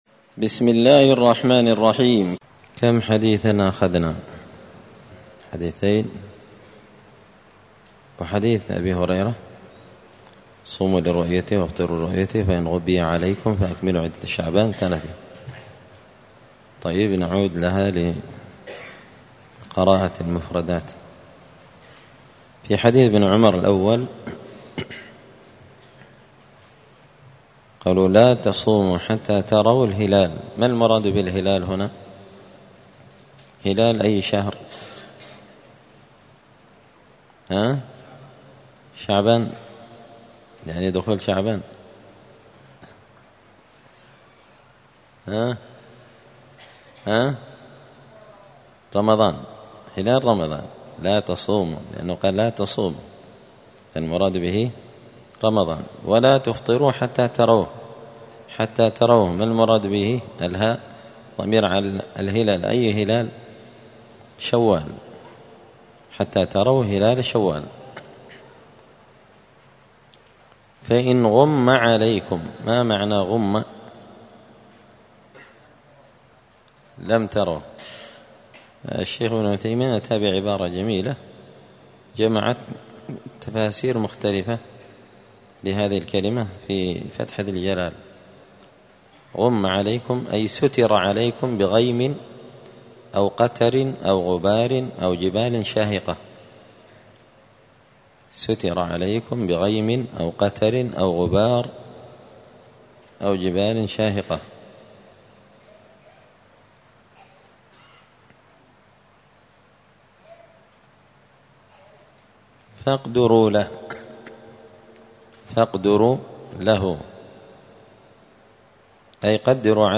دار الحديث السلفية بمسجد الفرقان بقشن المهرة اليمن
*الدرس الثالث (3) {تابع لأحاديث رؤية هلال رمضان…}*